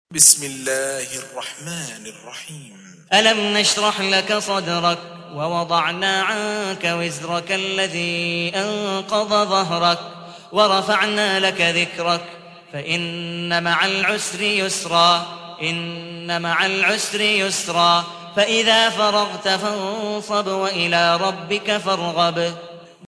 تحميل : 94. سورة الشرح / القارئ عبد الودود مقبول حنيف / القرآن الكريم / موقع يا حسين